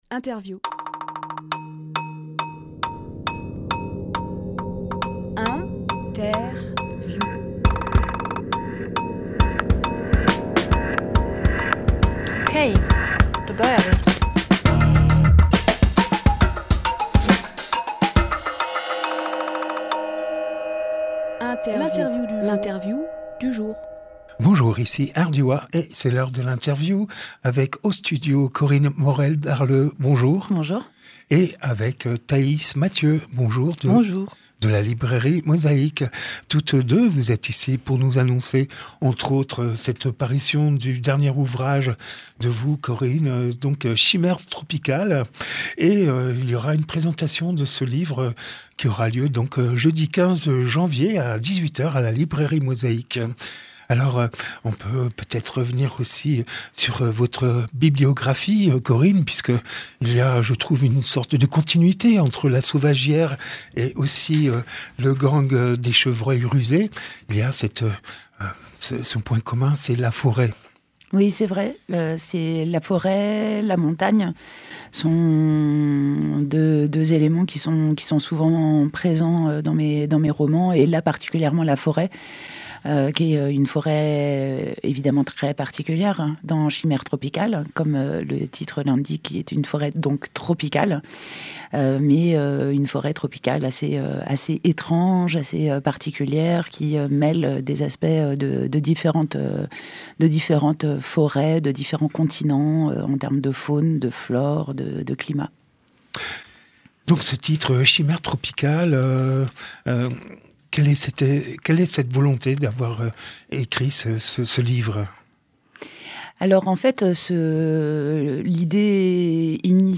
Interview Chimères Tropicales
lieu : Studio RDWA